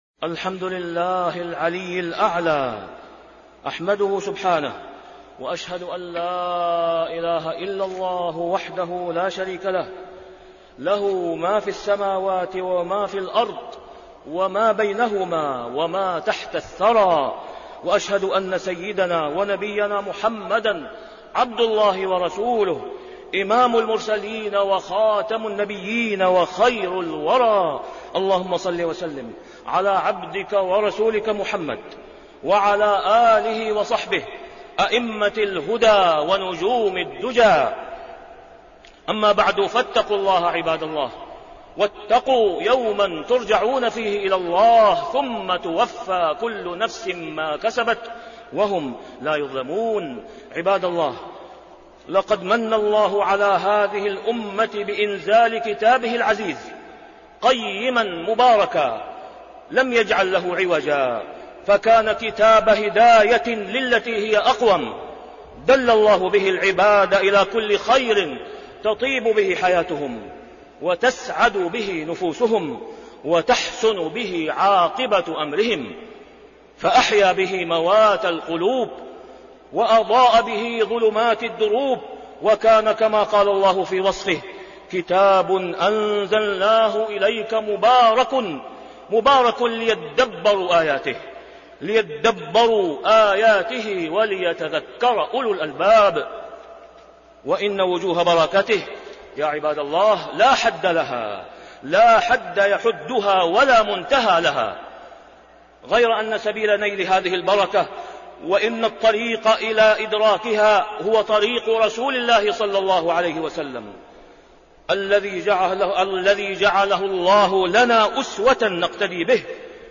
تاريخ النشر ١٢ رمضان ١٤٣٢ هـ المكان: المسجد الحرام الشيخ: فضيلة الشيخ د. أسامة بن عبدالله خياط فضيلة الشيخ د. أسامة بن عبدالله خياط وجوب تدبر القرآن